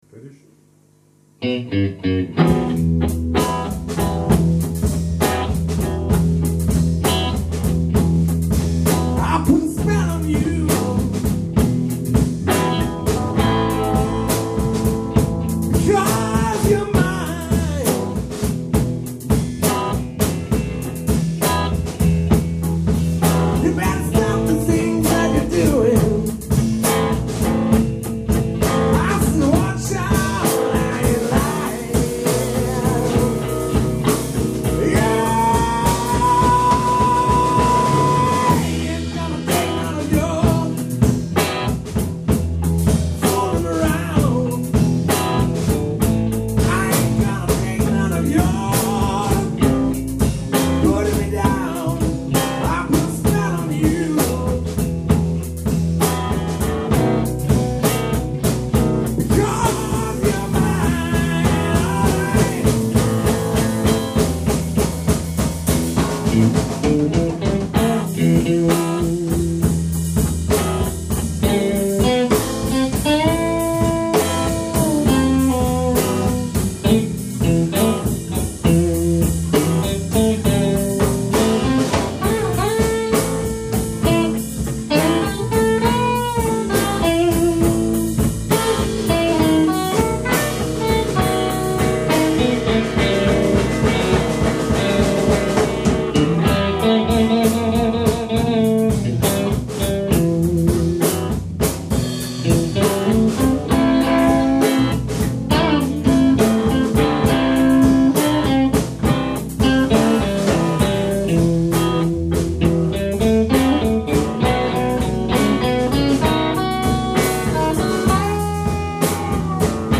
Rock & Roll Band!
Memories of Rock & Roll, Soul, Rhythm Blues.
Ehrliche, handgemachte Rock- Musik
Bass
Drums